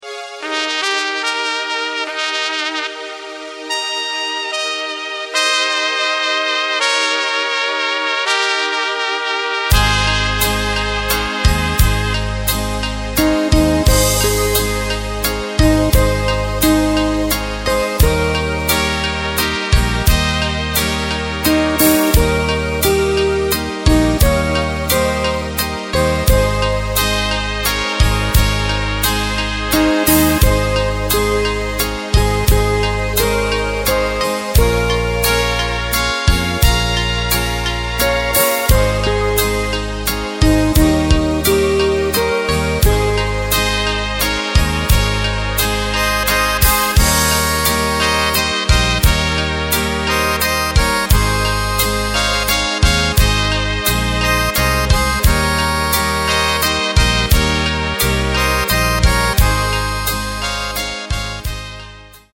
Takt:          3/4
Tempo:         73.00
Tonart:            Ab
Schöner Langsamer Walzer!